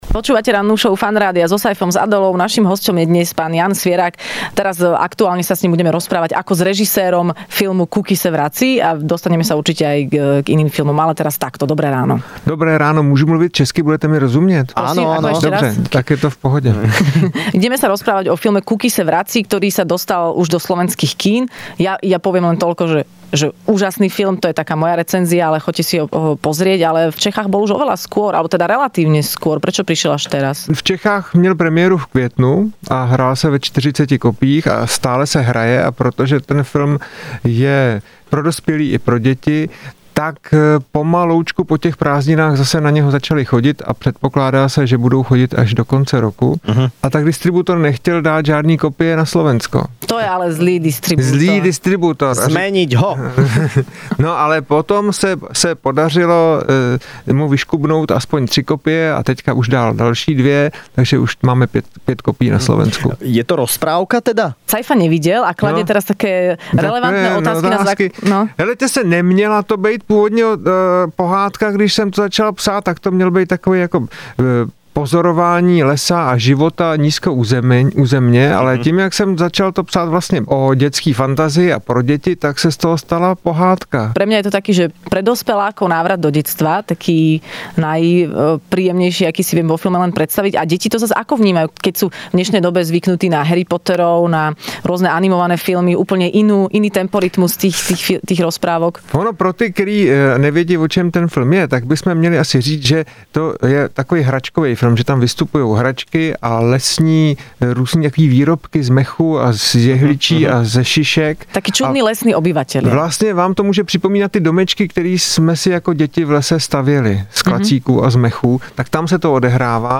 Hosťom v rannej šou Fun rádia bol režisér Jan Svěrák, ktorý porozprával o svojom novom filme Kuky se vrací...